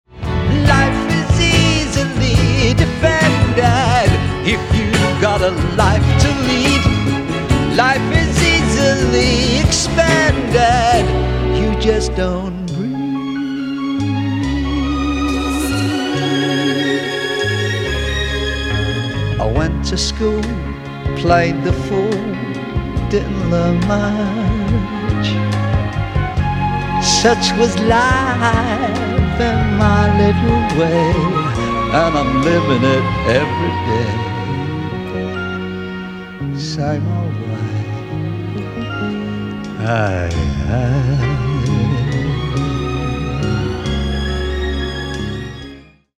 Lavishly orchestrated